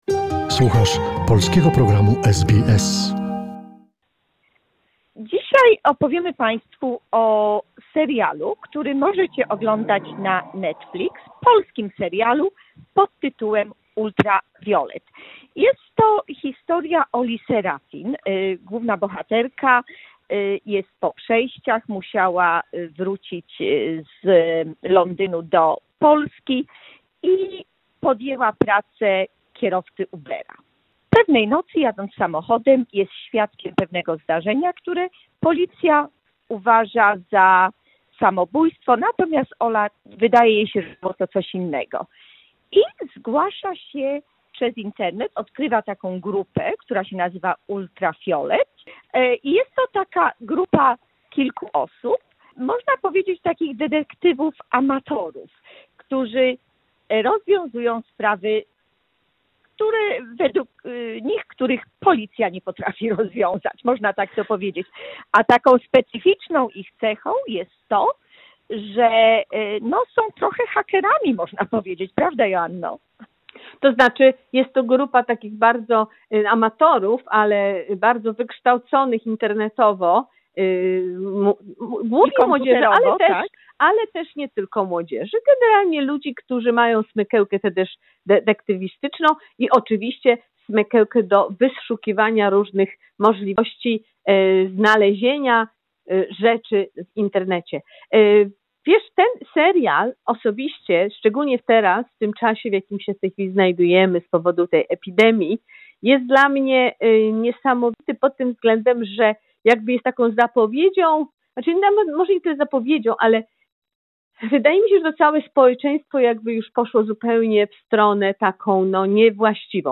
Recenzja filmowa : serial 'Ultraviolet'